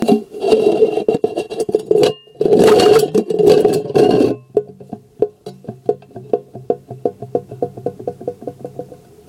罐子 " 滚动罐10
描述：通过沿着混凝土表面滚动各种尺寸和类型的罐头制成的声音。录音设备：第4代iPod touch，使用media.io转换。
标签： 轧制 锡罐
声道立体声